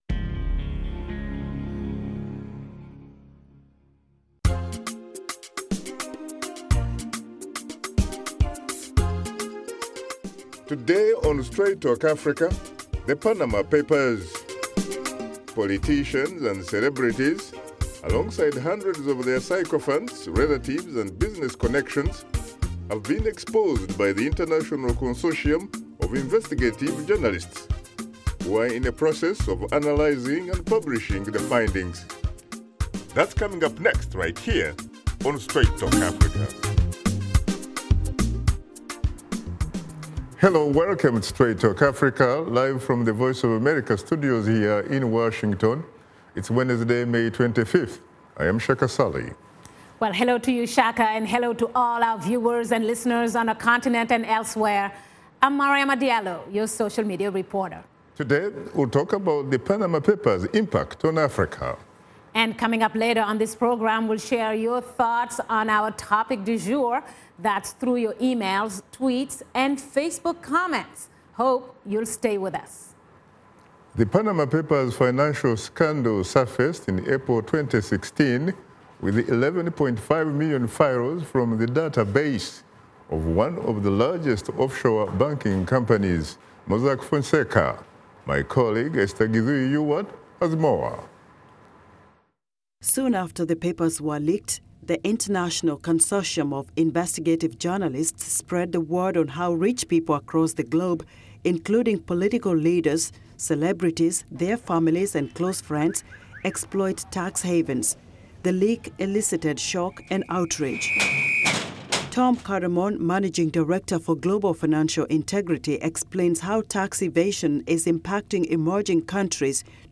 Join veteran journalist Shaka Ssali on Straight Talk Africa every Wednesday as he and his guests discuss topics of special interest to Africans, including politics, economic development, press freedom, health, social issues and conflict resolution.